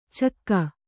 ♪ cakka